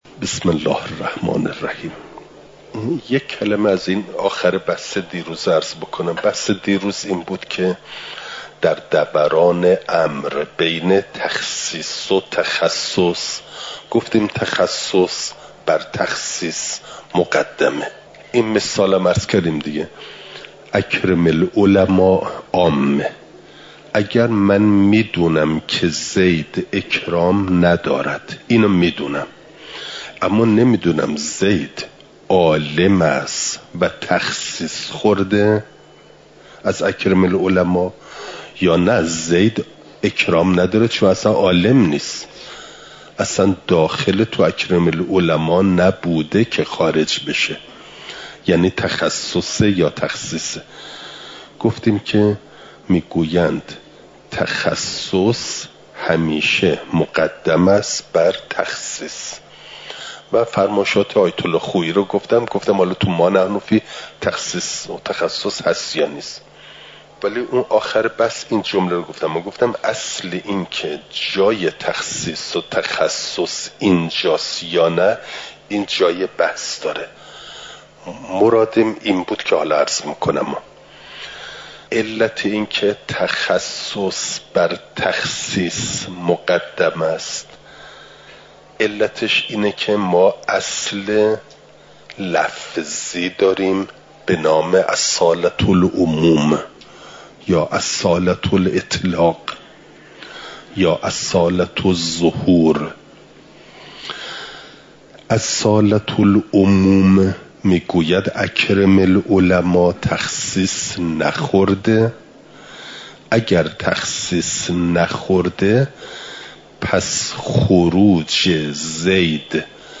امارات؛ قطع و ظن (جلسه۴۷) – دروس استاد